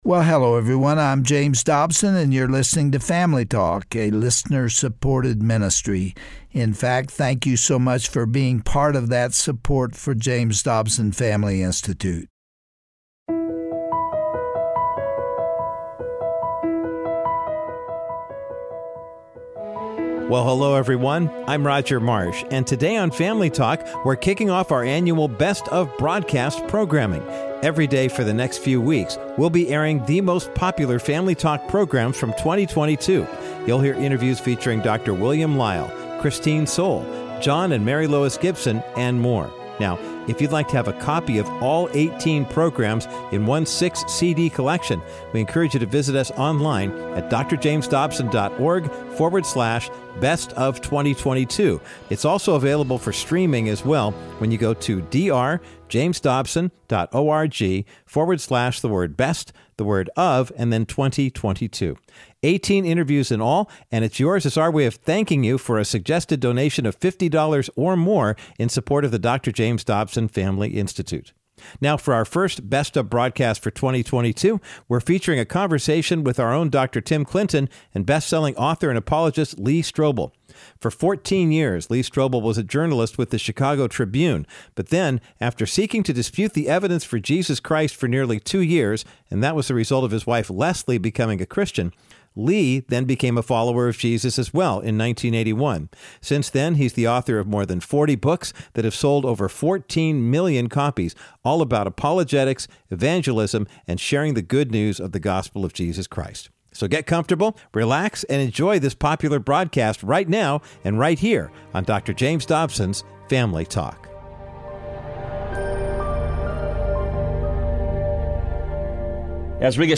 Guest(s):Lee Strobel